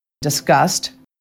Can you differentiate the words discussed and disgust as produced by native speakers in natural sentences?
discussed or disgust? (USA)